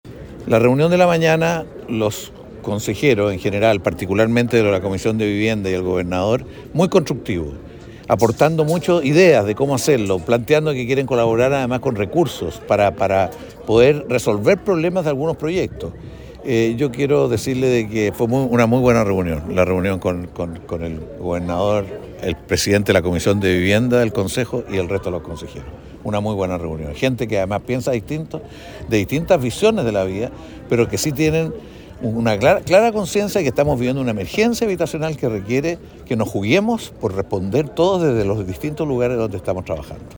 Cuña_Ministro-Carlos-Montes_.mp3